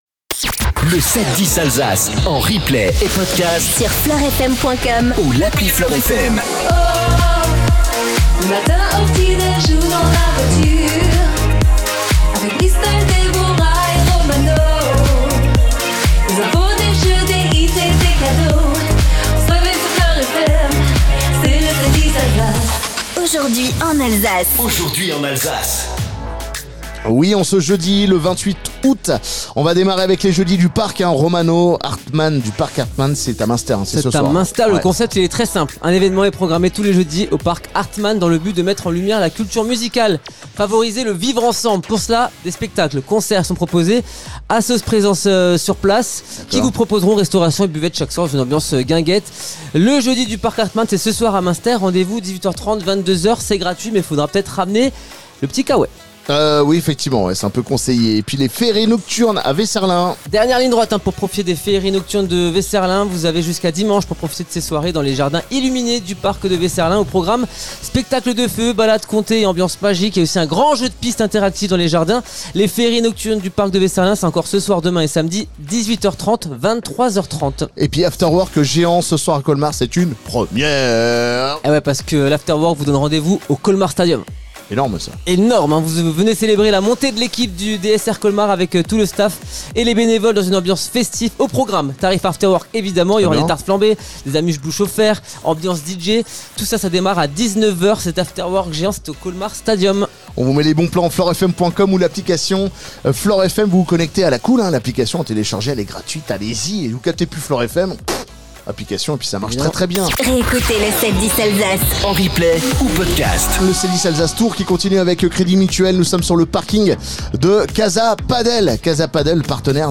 71 ALSACE FLOR FM MORNING PODCAST MUSHOUSE WEHR LUTTERBACH CREDIT MUTUEL ENTREPRISES LK FLORFM Jeudi 28 août 0:00 28 min 27 sec 28 août 2025 - 28 min 27 sec LE 7-10 DU 28 AOÜT Retrouvez les meilleurs moments du 7-10 Alsace Tour 2025, ce jeudi 28 août dans les rues de Cernay/ Guebwiller.